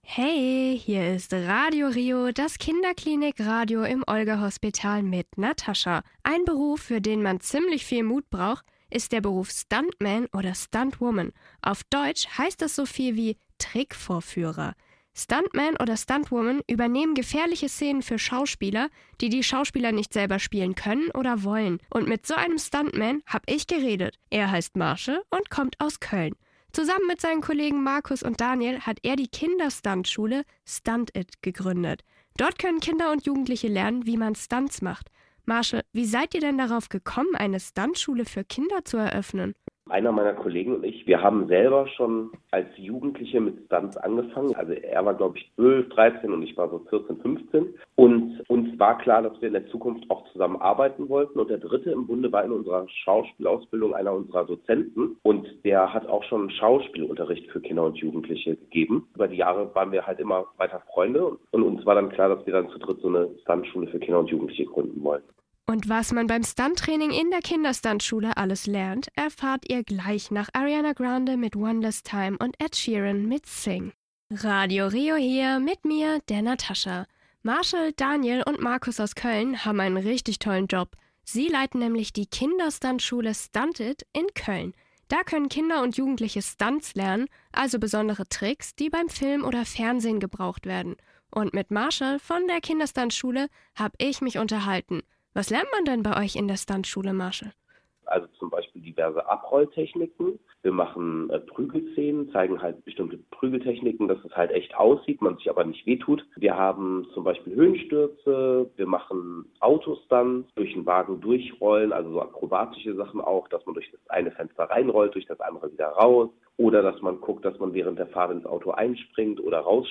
Im Interview mit Antenne 1
Interview-Radio-RiO-clean.mp3